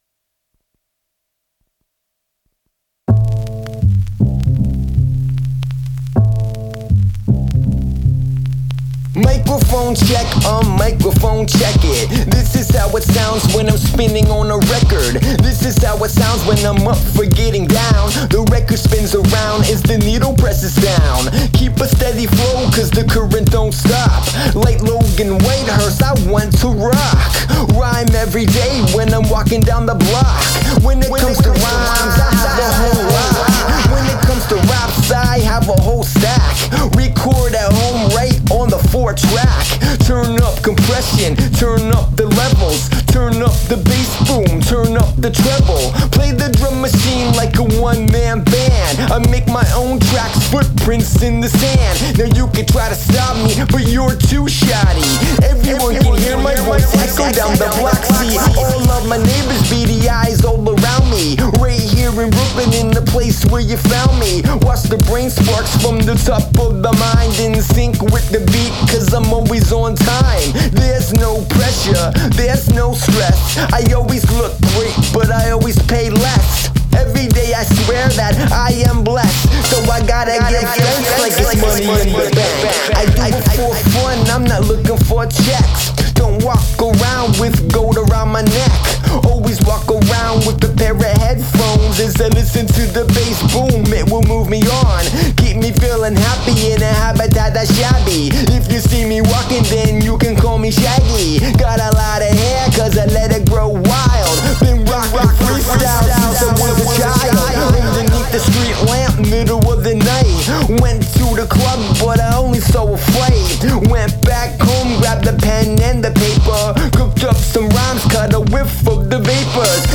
upright bass to lay down a mellow, jazzy foundation.
With its freewheeling groove